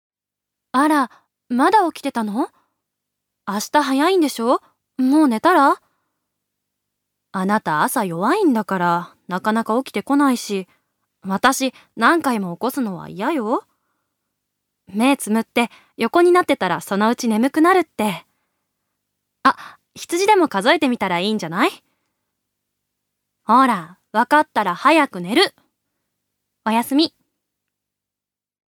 預かり：女性
セリフ１